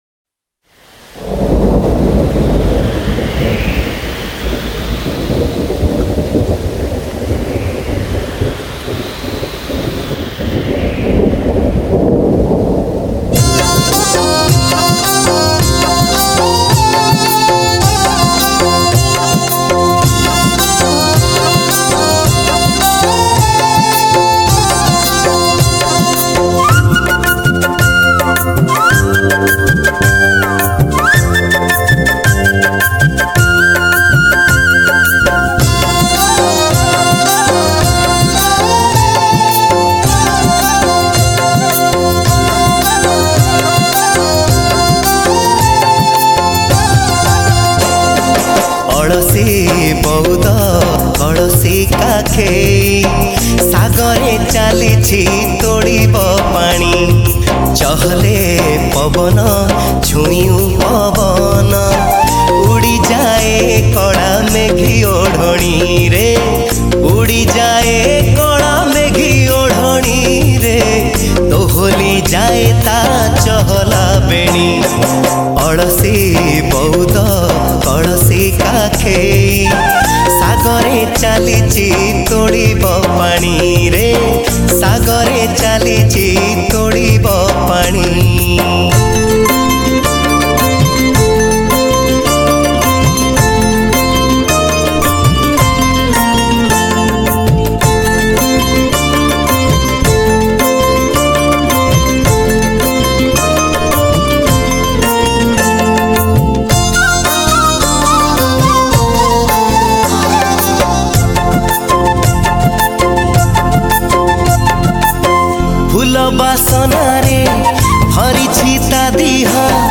Mansoon Special Romantic Odia Song